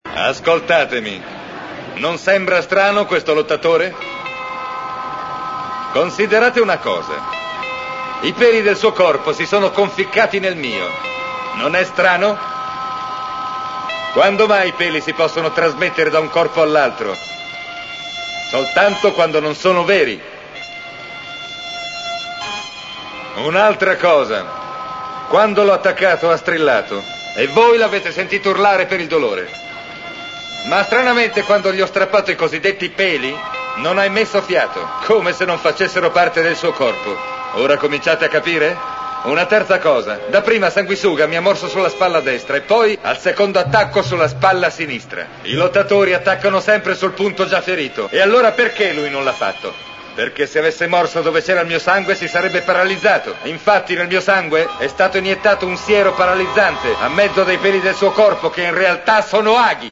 dal cartone animato "L'Uomo Tigre"